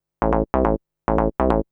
SNTHBASS057_DANCE_140_A_SC3.wav